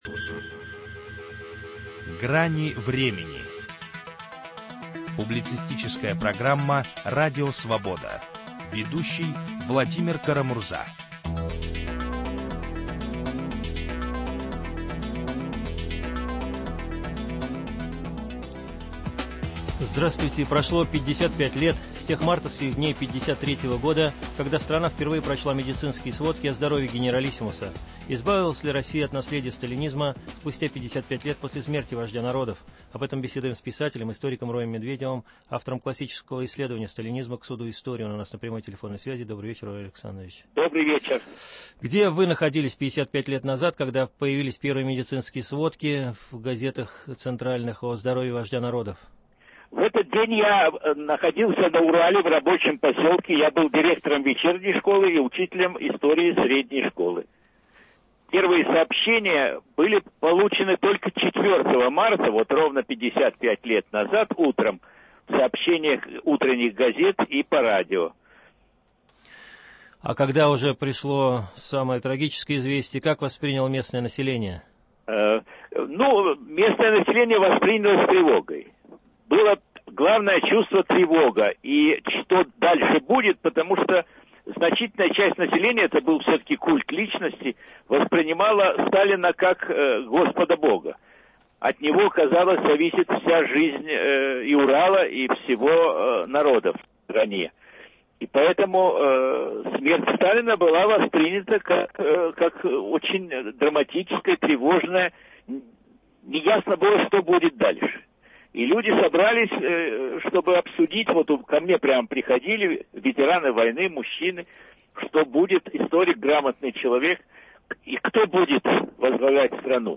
Можно ли считать Россию избавившейся от наследия сталинизма спустя 55 лет после смерти «вождя народов»? Об этом беседуем с писателем-историком Роем Медведевым, советским диссидентом, бывшим народным депутатом СССР, соучредителем Социалистической партии трудящихся.